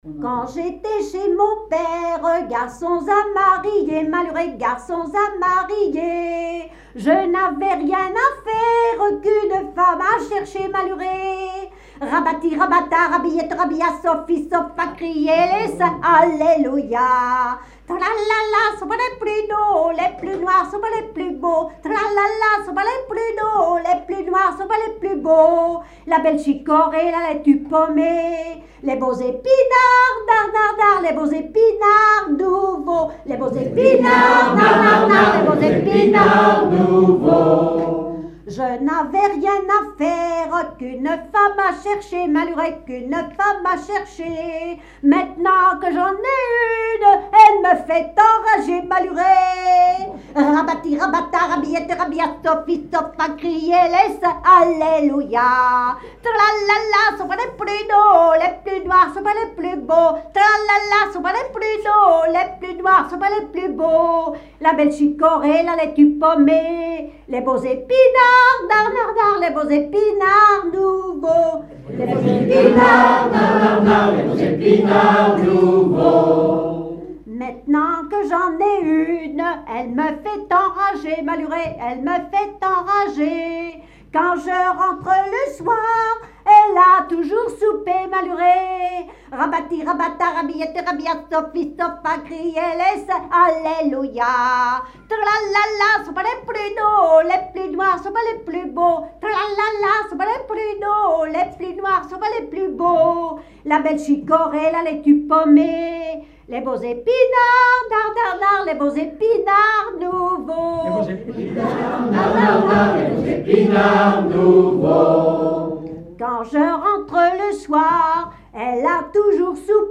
Genre laisse
Veillée (version Revox)
Pièce musicale inédite